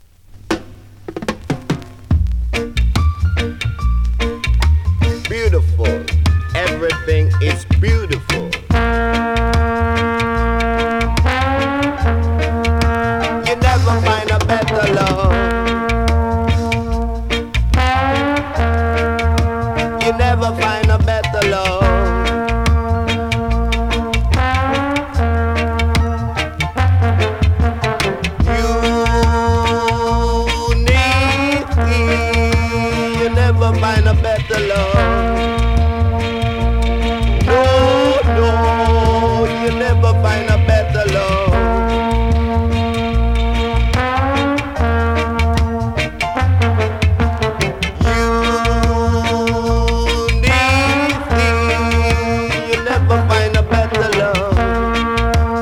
スリキズ、ノイズかなり少なめの